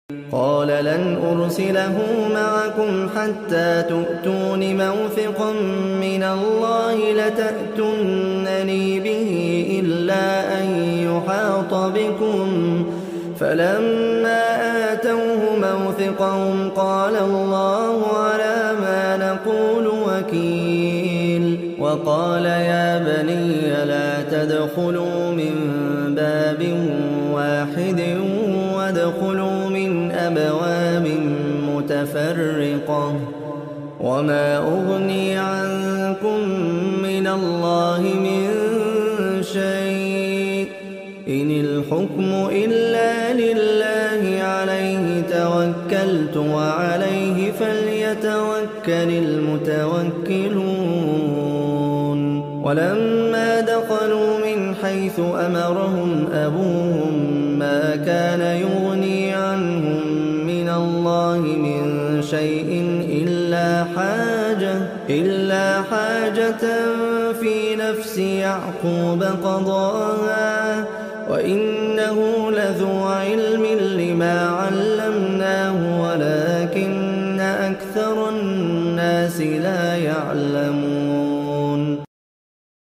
احسن تلاوة صوت عدب
تلاوة_القرآن